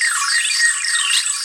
time_warp_healing_spell_loop2.wav